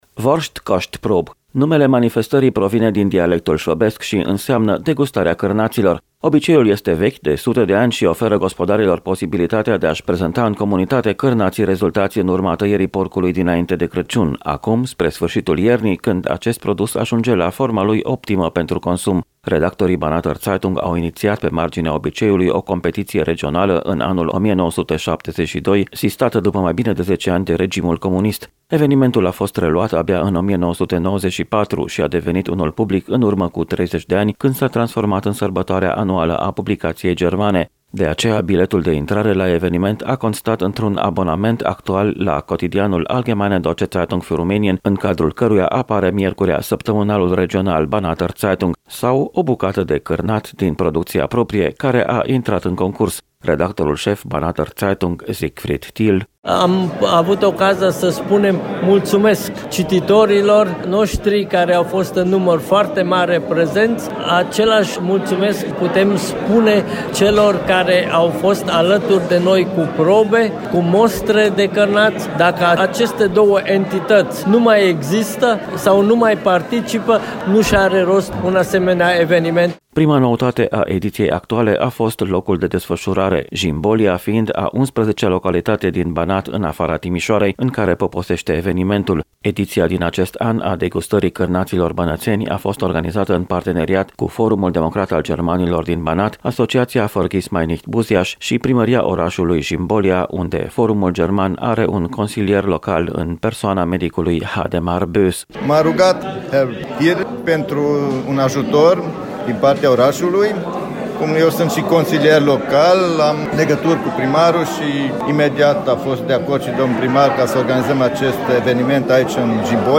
REPORTAJ | „Degustarea cârnaților bănățeni”, o ediție cu premiere la Jimbolia
Evenimentul a fost presărat cu muzică și dansuri populare șvăbești.